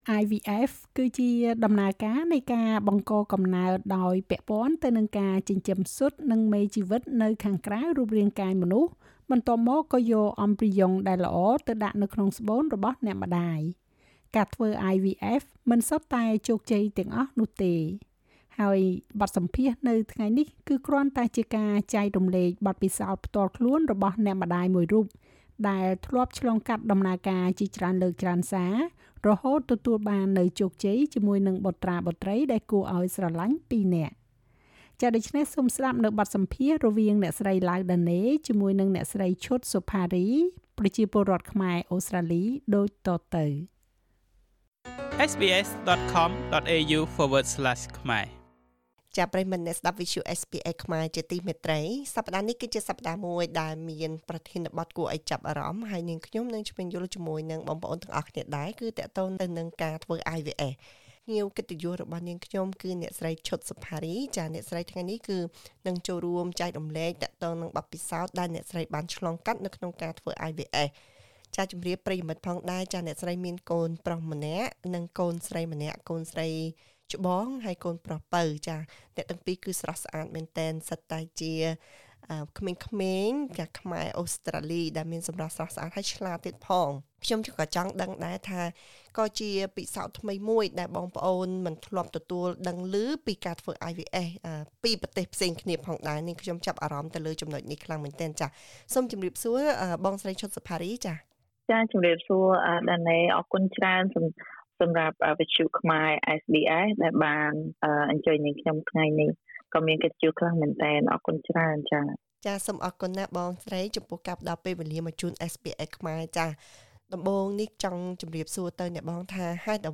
ហើយបទសម្ភាសន៍នៅថ្ងៃនេះ គឺគ្រាន់តែជាការចែករំលែកបទពិសោធន៍ផ្ទាល់ខ្លួនរបស់អ្នកម្តាយមួយរូប ដែលឆ្លងកាត់ដំណើរការជាច្រើនលើក ច្រើនសារ រហូតទទួលបានជោគជ័យ ជាមួយនឹងបុត្រាបុត្រីដ៏គួរឱ្យស្រឡាញ់ពីរនាក់។